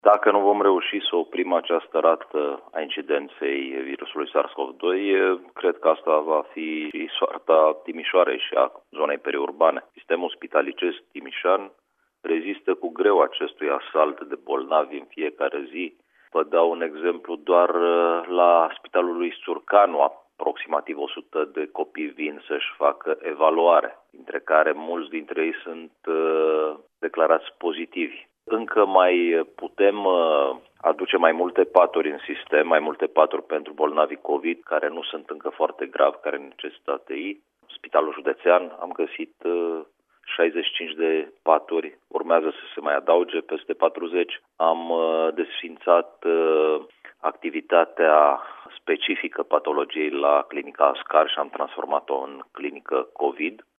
Prin urmare, nu este exclusă instituirea carantinei, a precizat, la Radio Timișoara, subprefectul de Timiș, Ovidiu Drăgănescu.
Subprefectul de Timiș a mai precizat, la Radio Timișoara, că la Deta se pregătește deschiderea unei secții covid ATI.